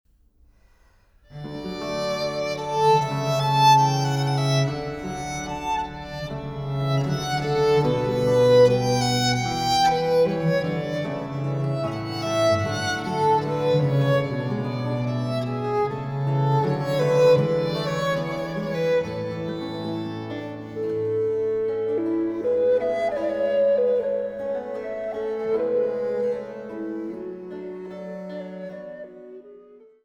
durch Flöten, Hackbrett und diverse Continuo-Instrumente